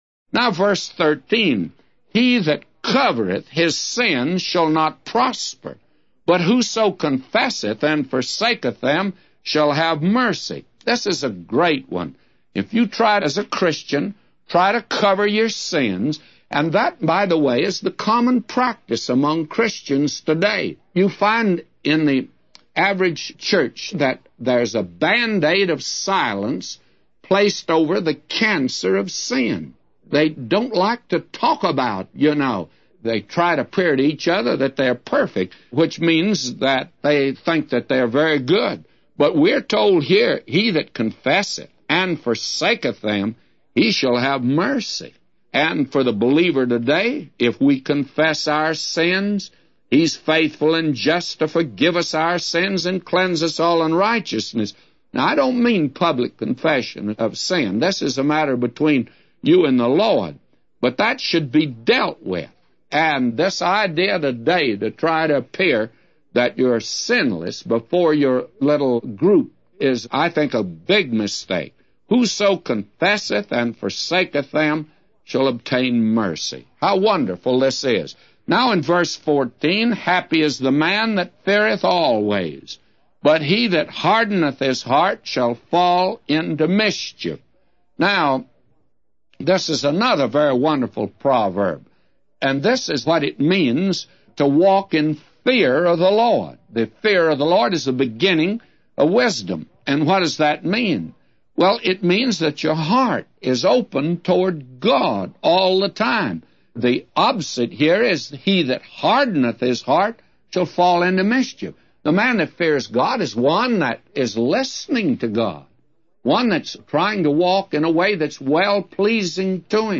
A Commentary By J Vernon MCgee For Proverbs 28:13-999